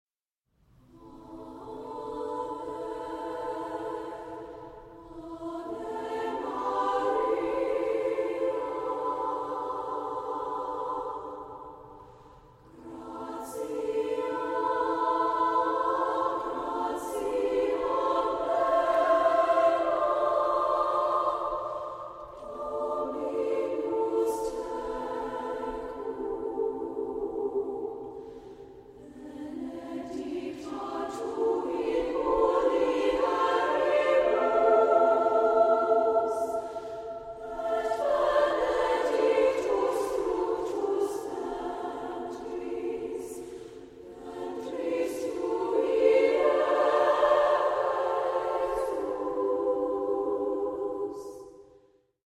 Voicing: ssaa Accompaniment: a cappella